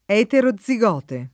eterozigote [ H tero zz i g0 te ]